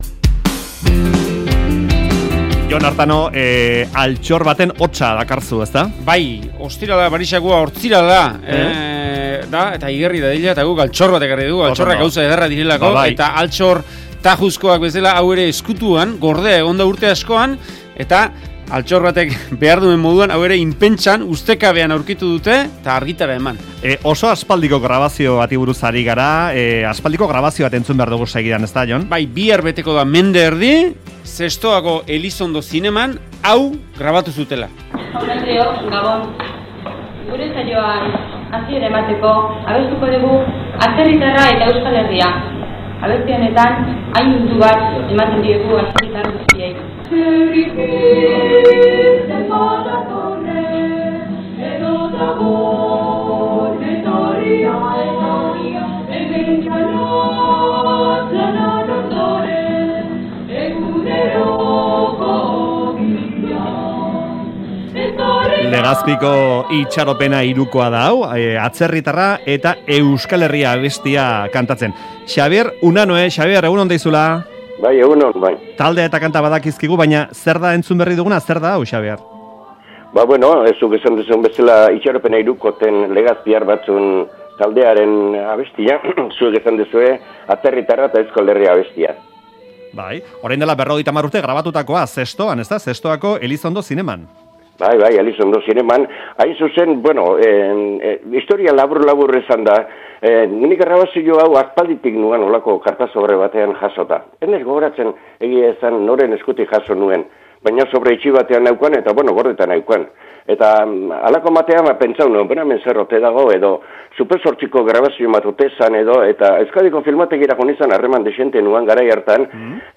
Audioa: Zestoko ikastolaren alde 1970ean egindako jaialdi baten grabazioa aurkitu dute.
Burgosko prozesua hasi aurretxoan Zestoan ospatutako jaialdi bat, bertako ikastolaren alde.